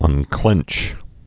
(ŭn-klĕnch)